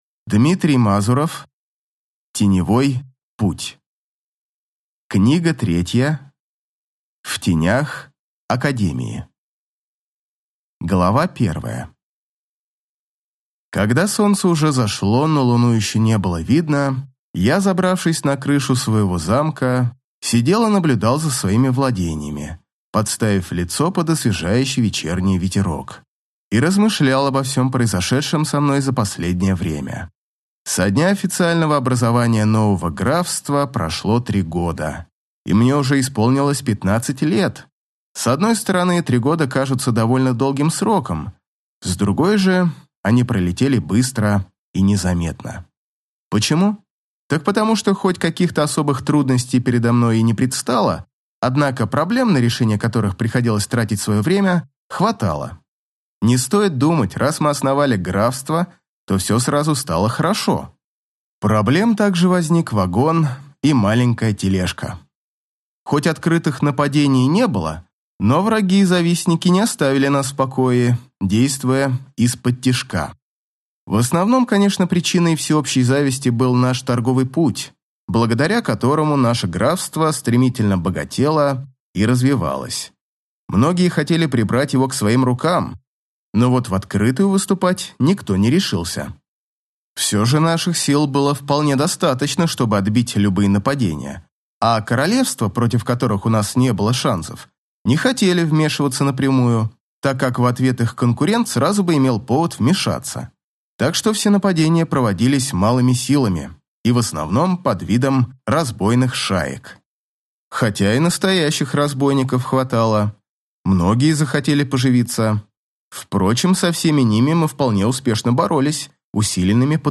Аудиокнига В тенях академии | Библиотека аудиокниг